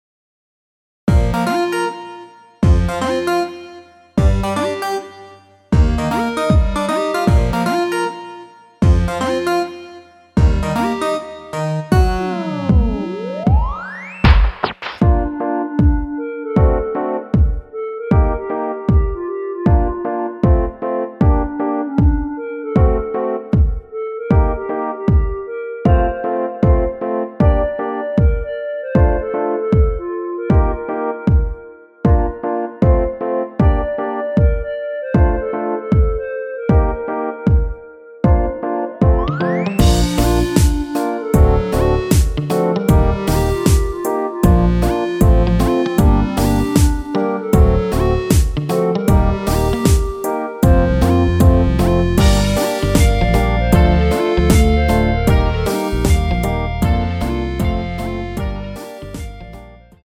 원키 멜로디 포함된 MR입니다.
Bb
앞부분30초, 뒷부분30초씩 편집해서 올려 드리고 있습니다.
중간에 음이 끈어지고 다시 나오는 이유는